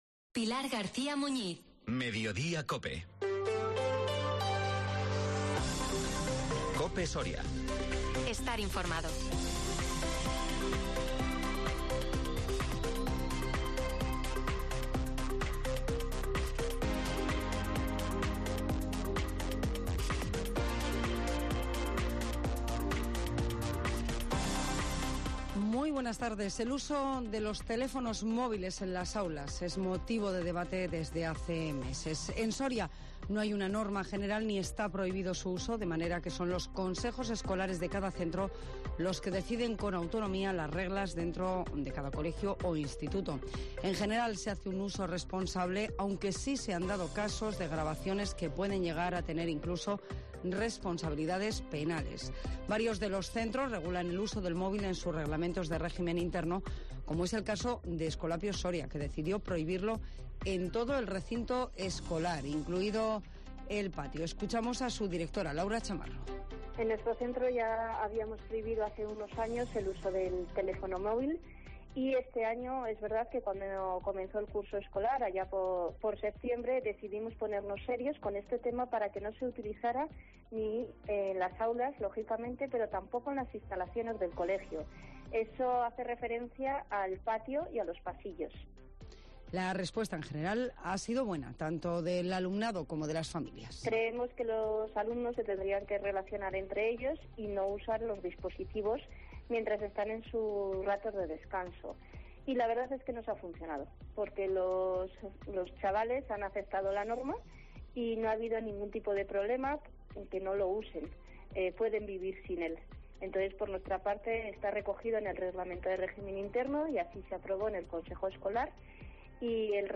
AUDIO: Las noticias en COPE Soria